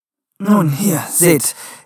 Sie ist in Mono und 48 kHz, und dennoch ist eine Dopplung zu hören.